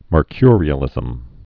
(mər-kyrē-ə-lĭzəm)